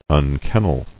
[un·ken·nel]